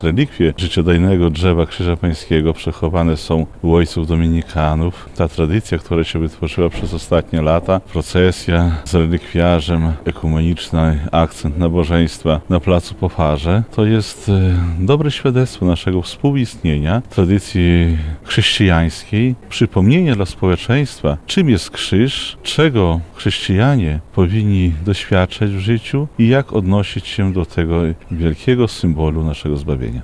– To ważny moment w Lublinie – mówi prawosławny arcybiskup lubelski i chełmski Abel: – Relikwie życiodajnego Drzewa Krzyża Pańskiego przechowywane są u o. dominikanów.